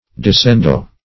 Disendow \Dis`en*dow"\, v. t. To deprive of an endowment, as a church.
disendow.mp3